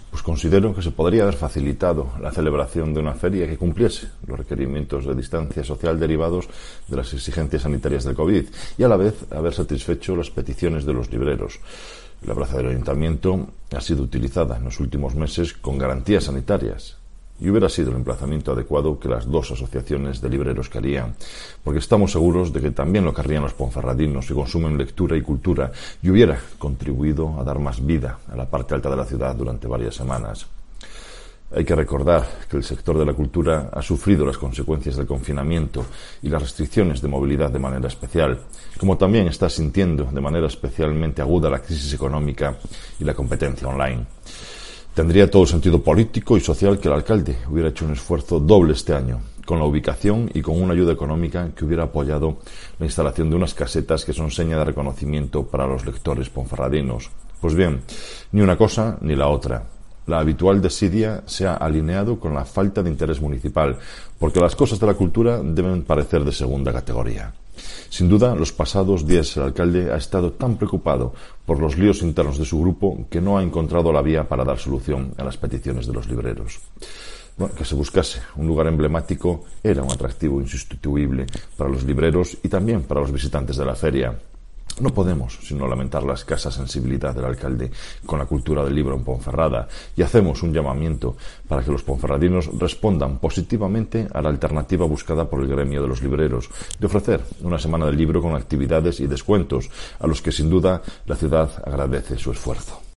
AUDIO: Escucha aquí las palabras de Marco Morala, portavoz de los populares en la capital berciana